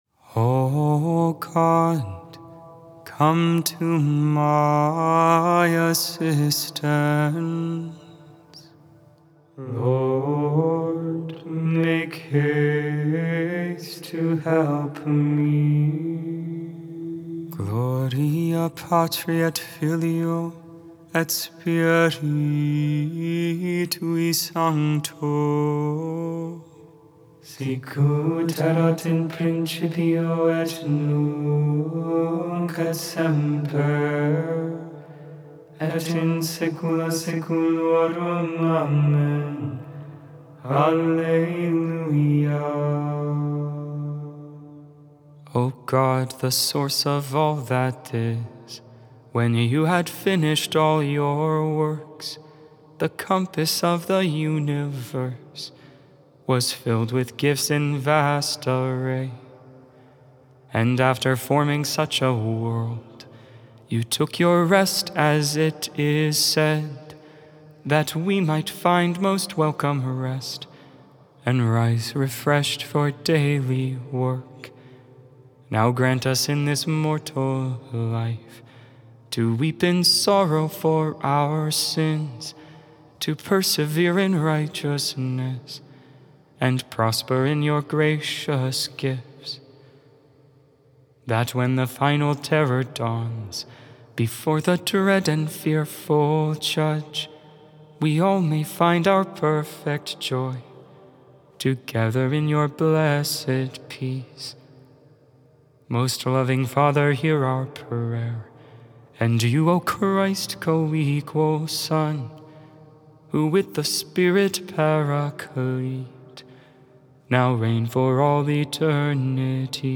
Solemn tone
Hymn